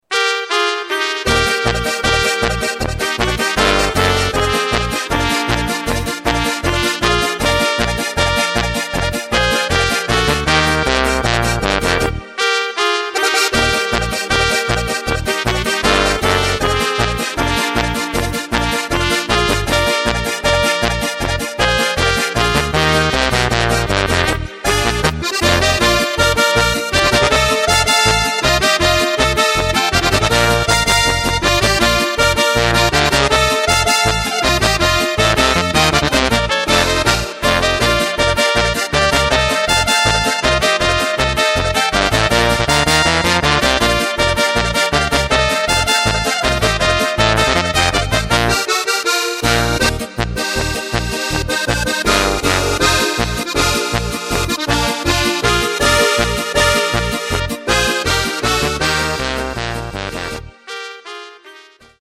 Tempo:         156.50
Tonart:            Eb
Flotte Polka Instrumental!